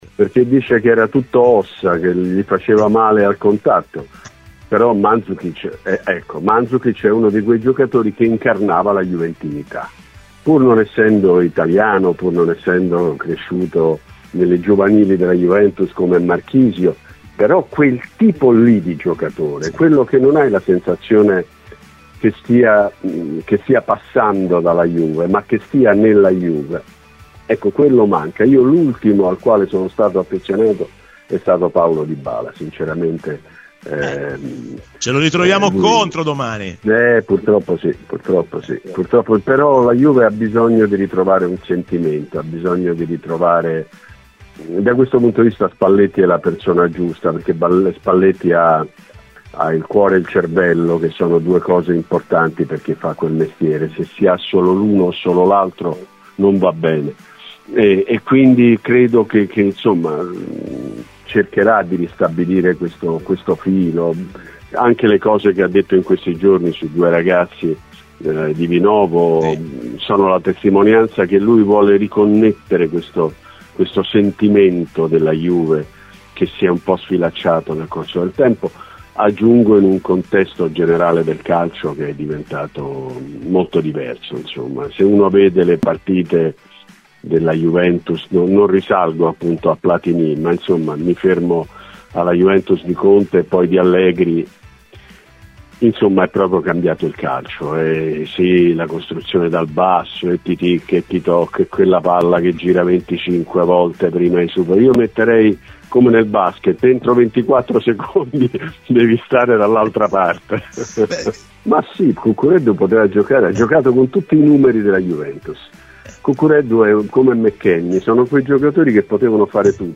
Walter Veltroni è intervenuto a Radio Bianconera.